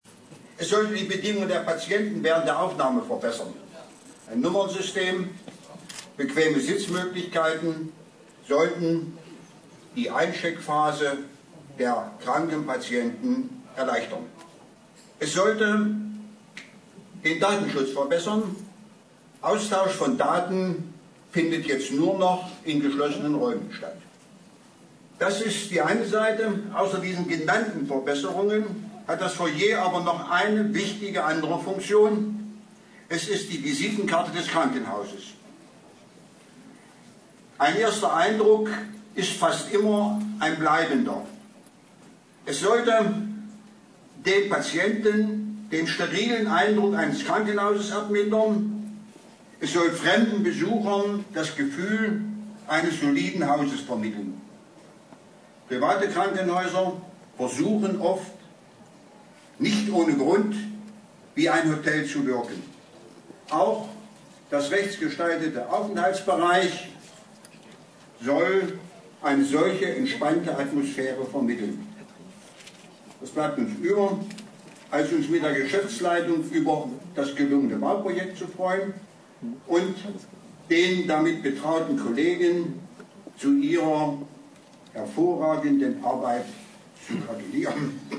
Grußwort